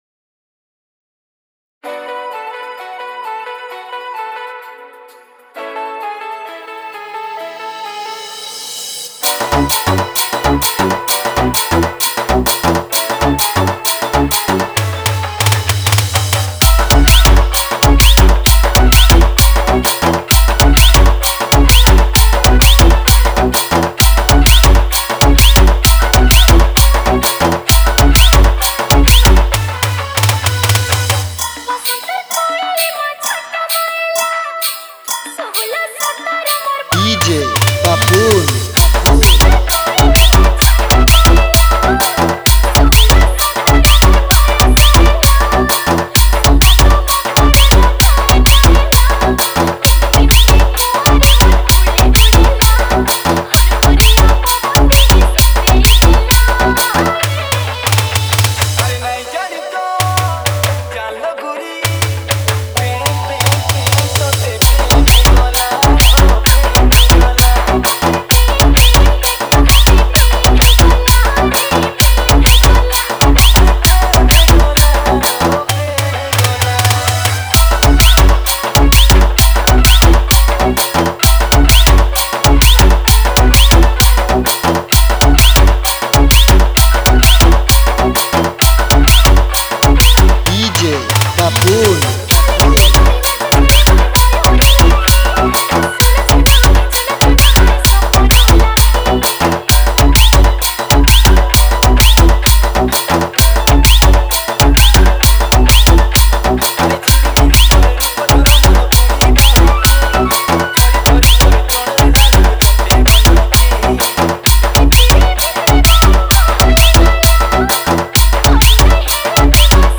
Category:  Sambalpuri Dj Song 2021